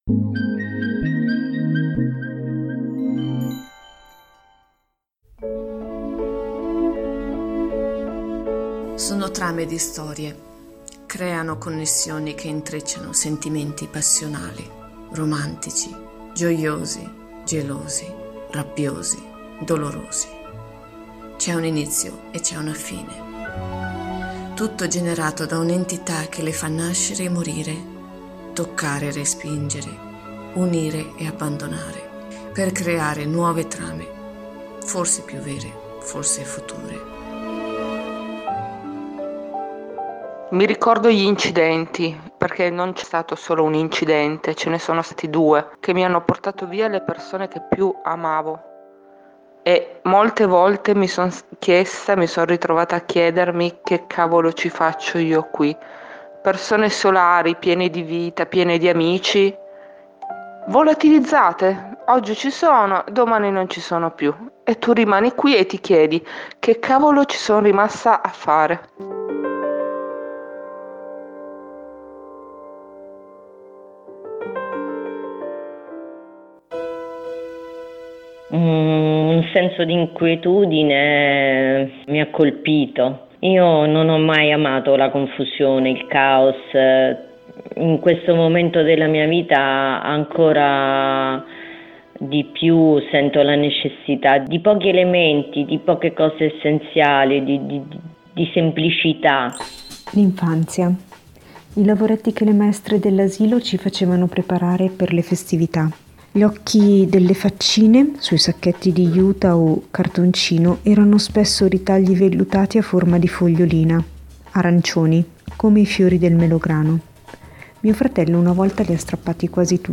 Ricordi e vissuti stimolati dalla visione di un dipinto, già metafora di una testimonianza impressa, vengono raccontati all'artista tramite registrazioni audio, dalla cui interazione nasce una nuova opera, che viene in seguito risottoposta alle impressioni, e così via, in una sorta di trasmissione orale pittorica di esistenze condivise.